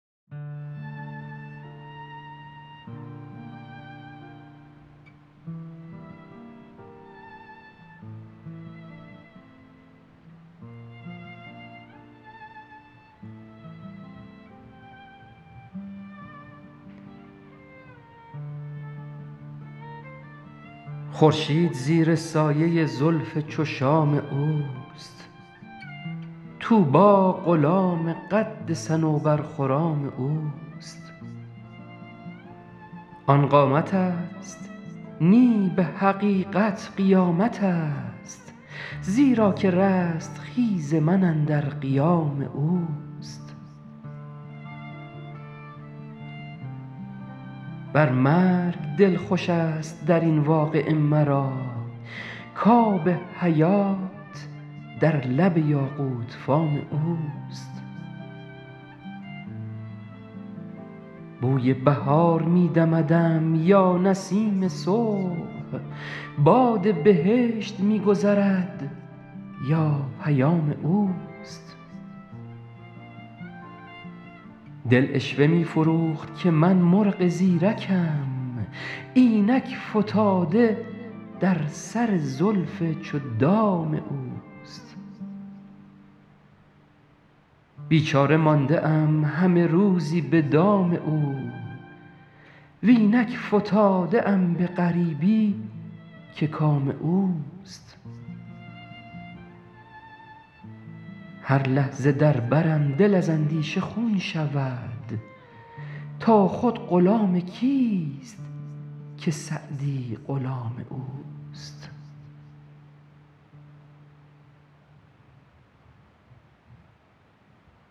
خوانش